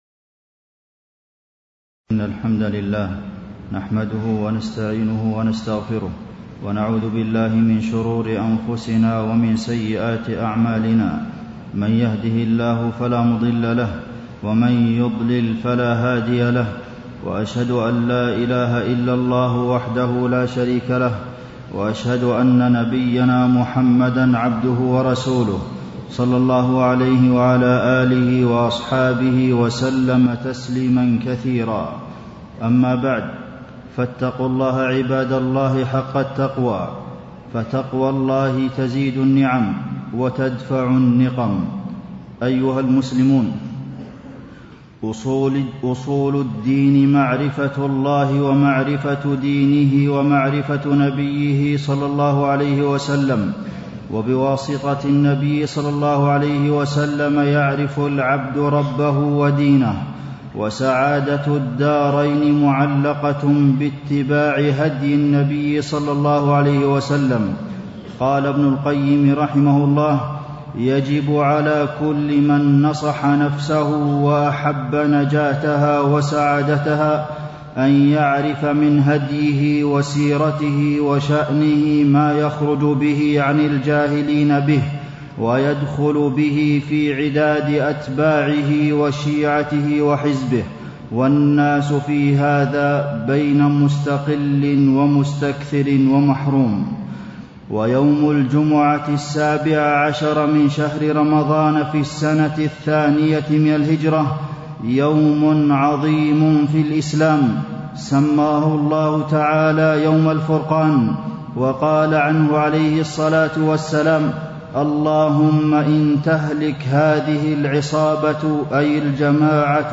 تاريخ النشر ١٠ رجب ١٤٣٥ هـ المكان: المسجد النبوي الشيخ: فضيلة الشيخ د. عبدالمحسن بن محمد القاسم فضيلة الشيخ د. عبدالمحسن بن محمد القاسم غزوة بدر والنصر القادم